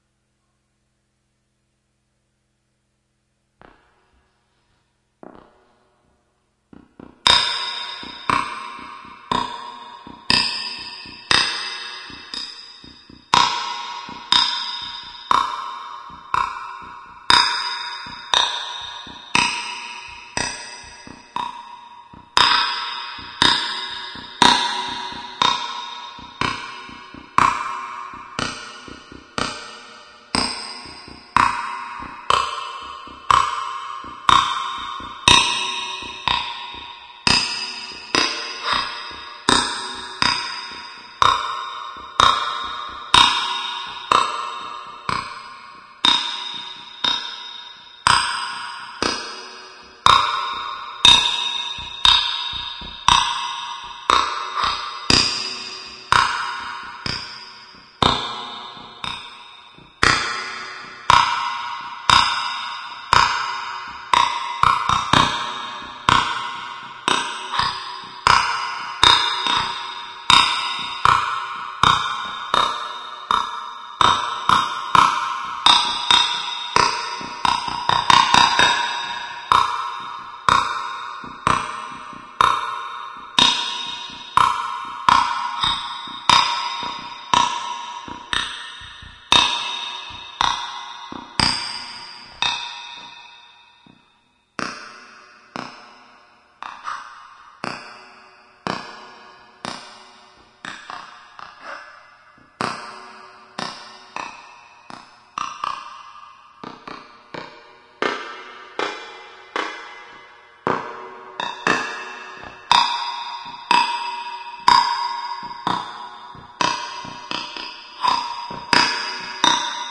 Contact mic inside piano » Inside piano contact mic twang
描述：A twanging sound recorded inside a piano with a contact mic
标签： insidepiano contactmic twang
声道立体声